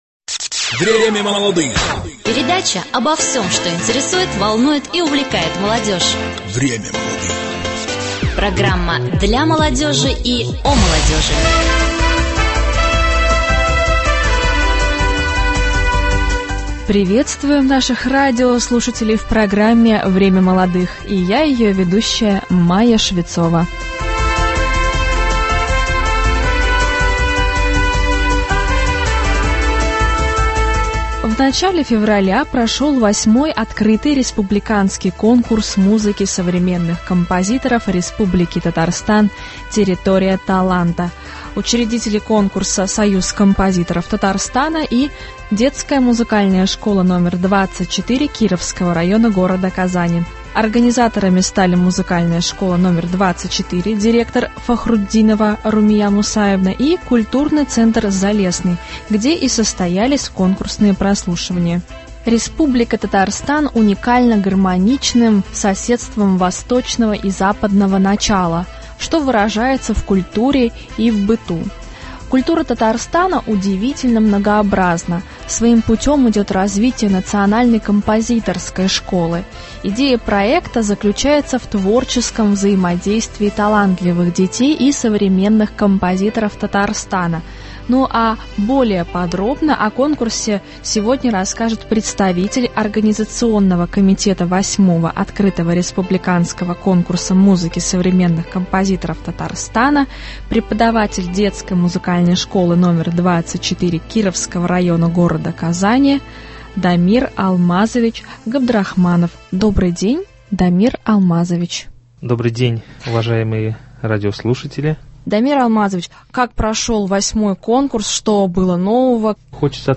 Открытый Республиканский конкурс музыки современных композиторов РТ «Территория таланта». Гость студии